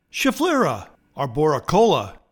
Pronounciation:
Scha-FLIR-a are-bor-i-KO-la